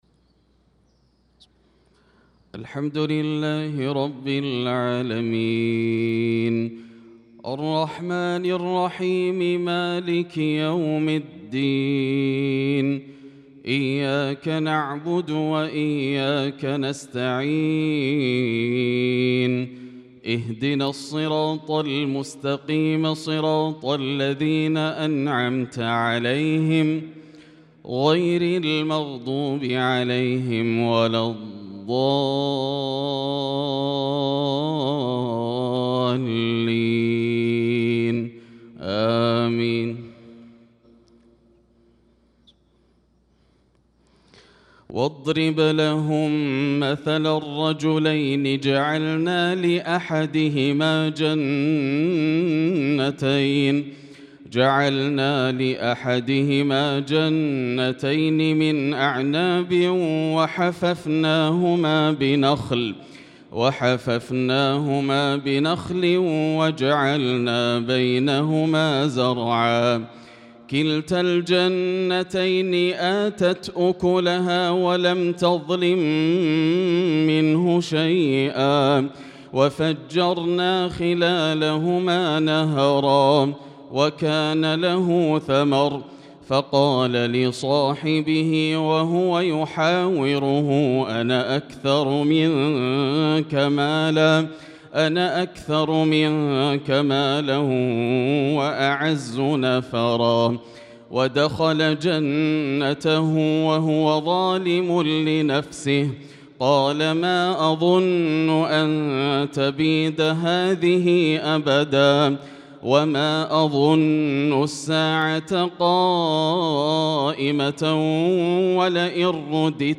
صلاة الفجر للقارئ ياسر الدوسري 10 ذو القعدة 1445 هـ
تِلَاوَات الْحَرَمَيْن .